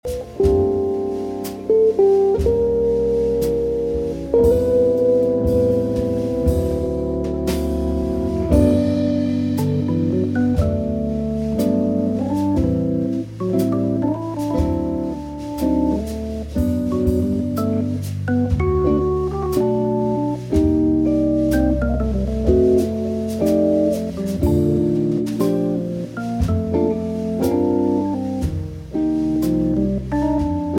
chitarra
batteria
hammond
inciso in California
è eseguito in trio